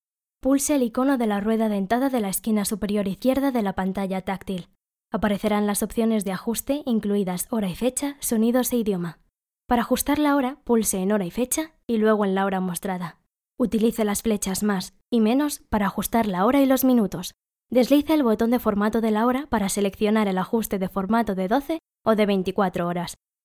Locutora y actriz de doblaje.
Voz jóven, cercana y natural.
kastilisch
Sprechprobe: eLearning (Muttersprache):
Young, close and natural voice.